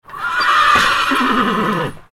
Barn Horse Whinny Sound Effect
Adult horse whinnies loudly in a barn on a farm. Horse noise neigh.
Barn-horse-whinny-sound-effect.mp3